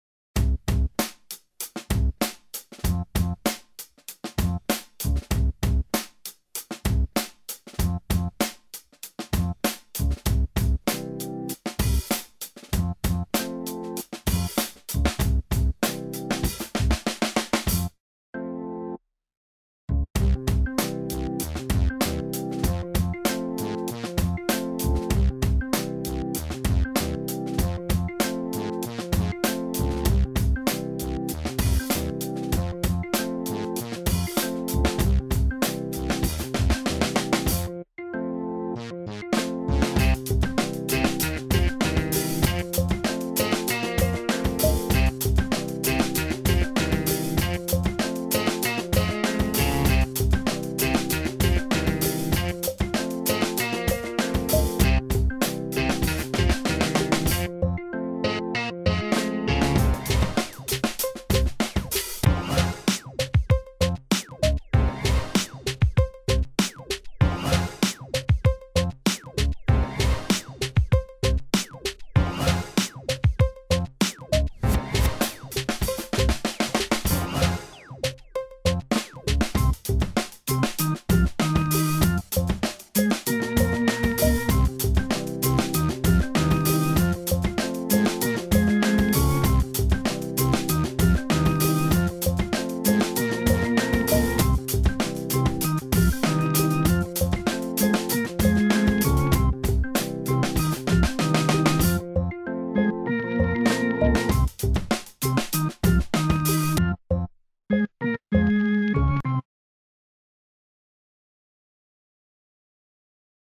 Το μουσικό θέμα
Mousiko-thema.wma